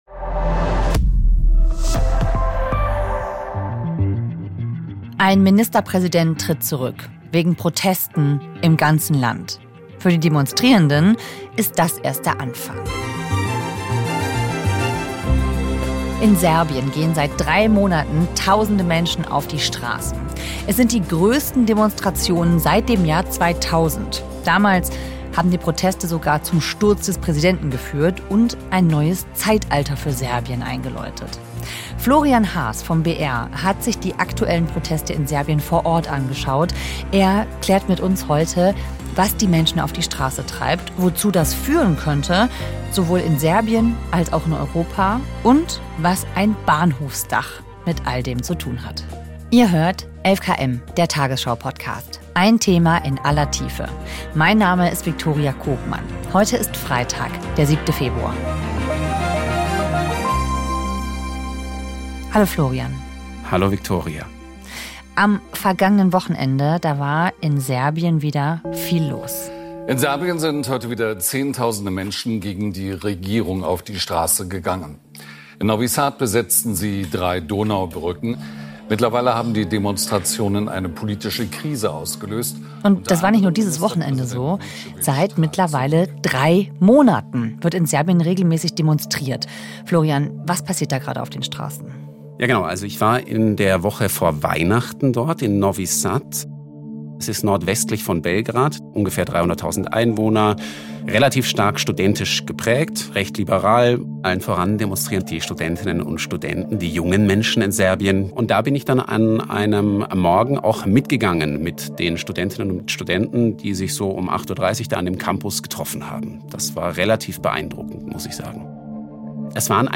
Er berichtet in dieser 11KM Folge davon, ob die Proteste dazu führen könnten, die Machtverhältnisse im Land dauerhaft zu verändern.